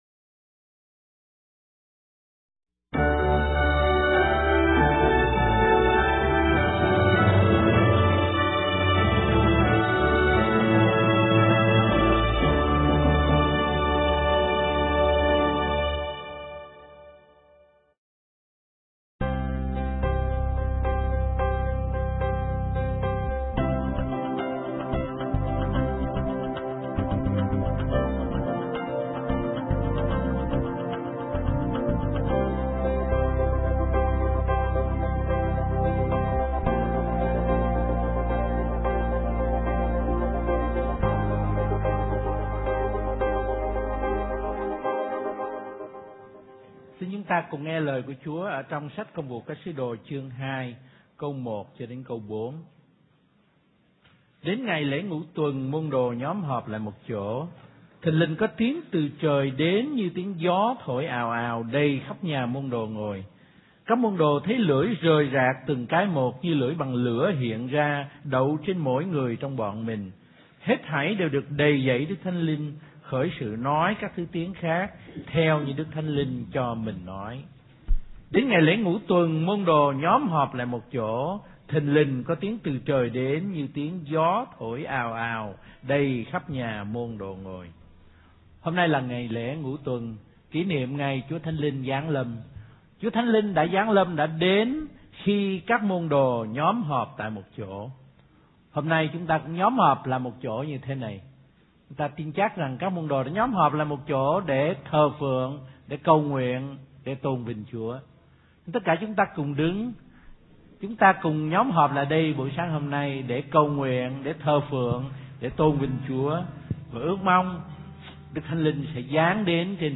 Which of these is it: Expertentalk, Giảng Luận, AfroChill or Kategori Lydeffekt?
Giảng Luận